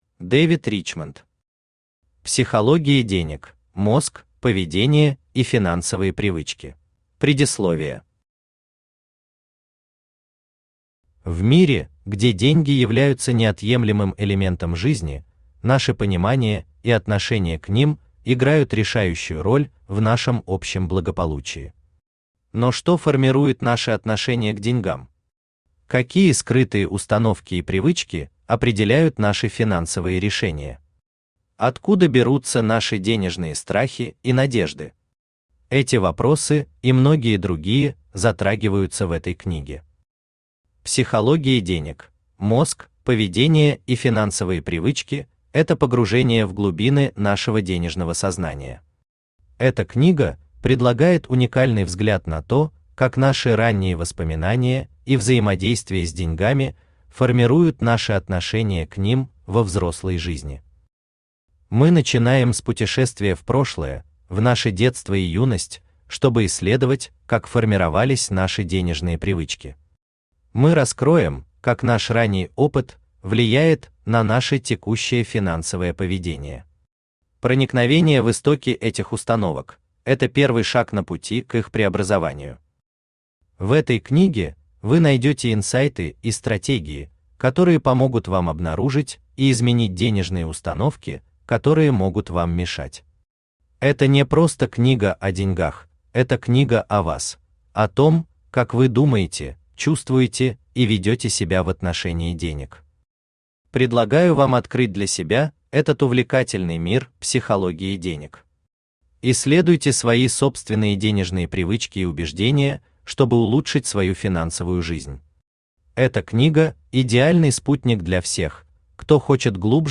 Aудиокнига Психология денег: мозг, поведение и финансовые привычки Автор Дэвид Ричмонд Читает аудиокнигу Авточтец ЛитРес.